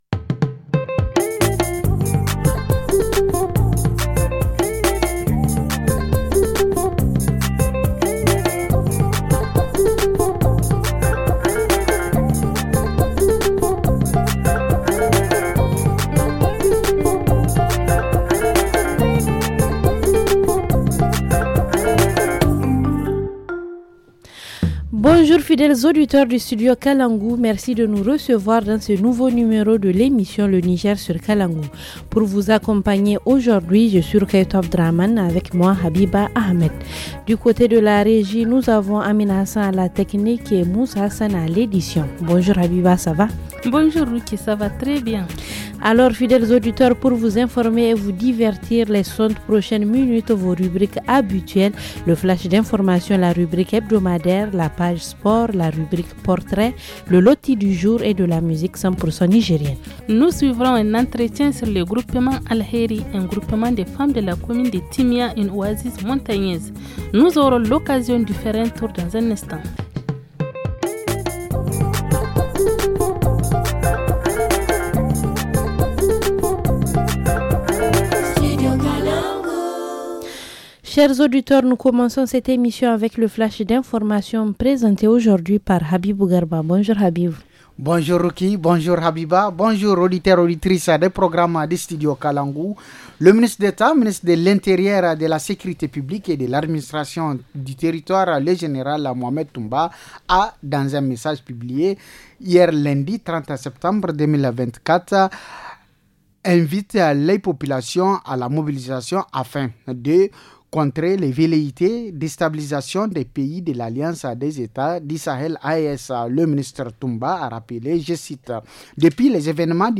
Dans l’émission de ce 01 octobre : Entretien avec le groupement des femmes Alheri, de Timia. Campagne de vaccination contre la rage à Diffa. Sensibilisation sur l’accès aux terres agricoles dans la région de Maradi.